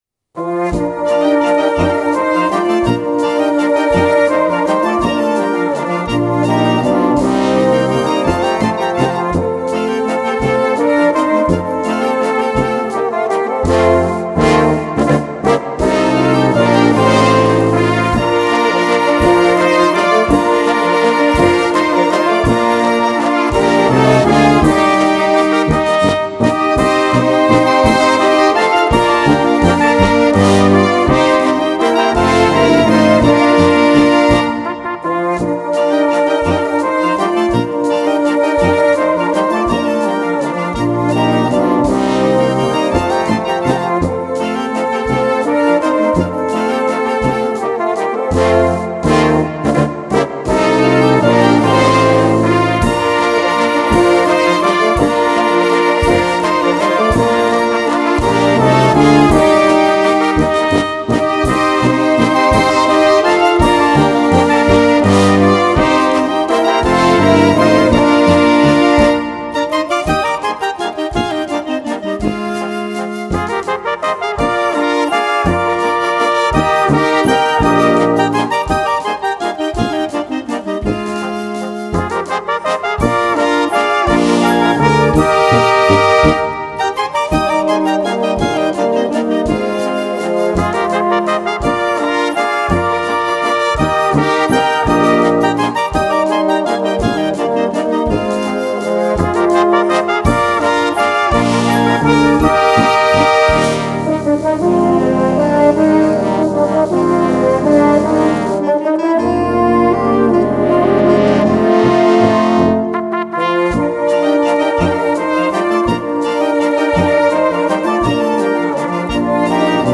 Walzer für Blasmusik